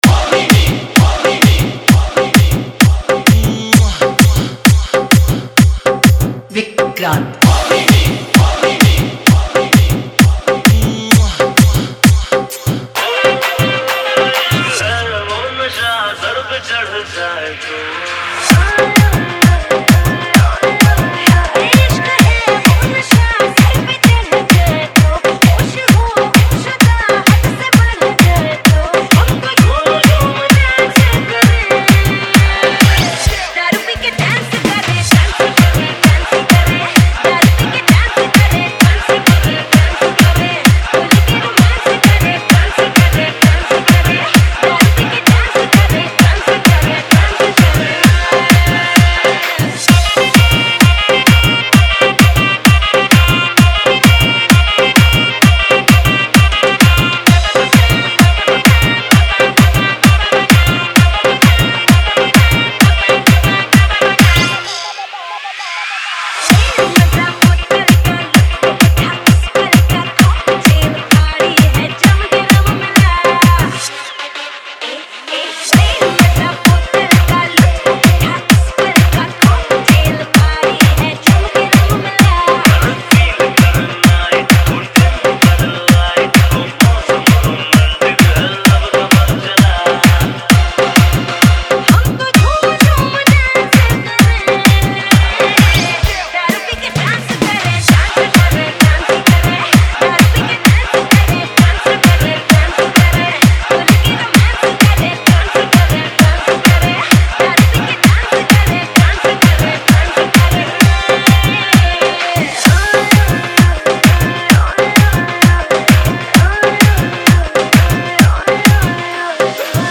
Category : Bollywood Edm Club Dance Remix Songs